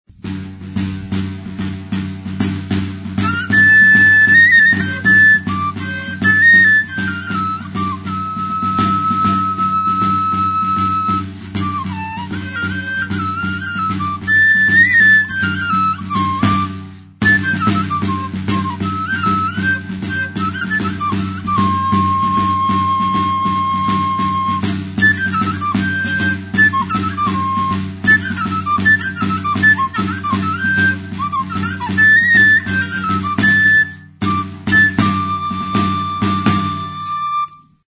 Music instrumentsTAMBOR
Membranophones -> Beaten -> Stick-beaten drums
CHARRADA.
Oiartzun, 2017-07-09.
Recorded with this music instrument.